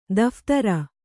♪ daphtara